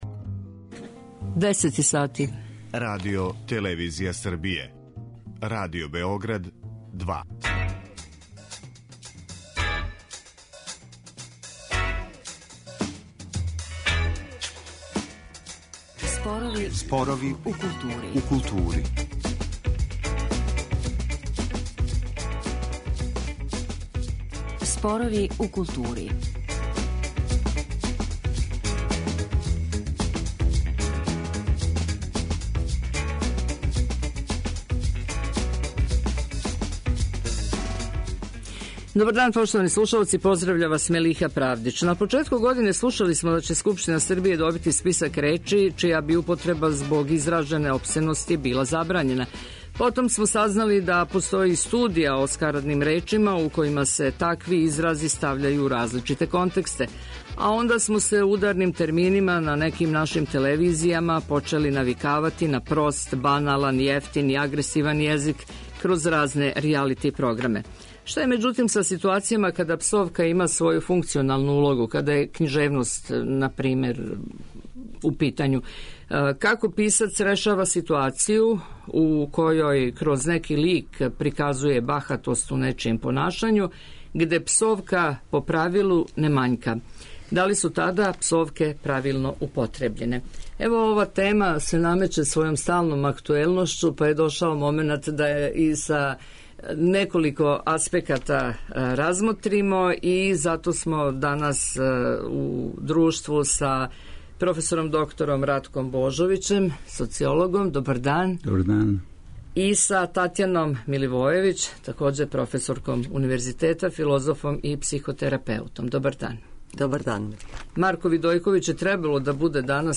О овим темама данас расправљамо